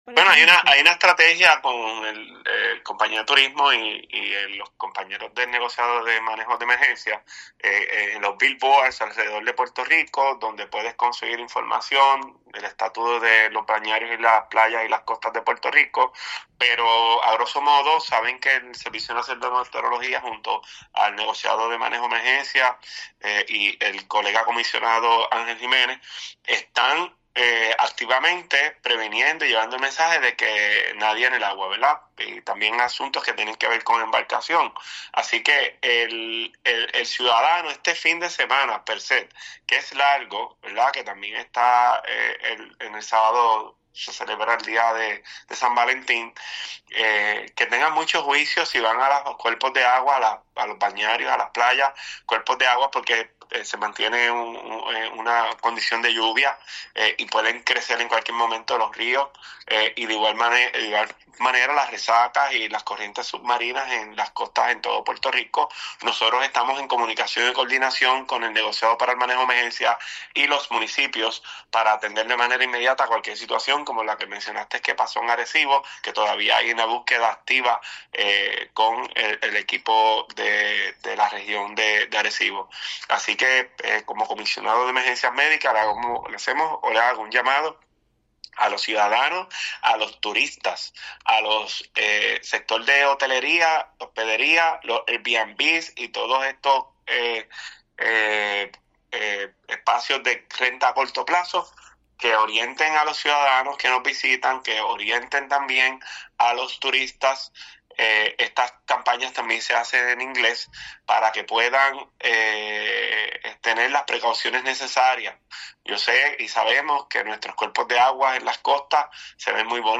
El funcionario recalcó que como Comisionado de Emergencias Médicas hace un llamado a los ciudadanos, a los turistas, a los sectores de hotelería, hospedería, Airbnb y todos estos espacios de renta a corto plazo que orienten a los ciudadanos que nos visitan especialmente a los turistas.